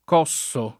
Cosso [ k 0SS o ]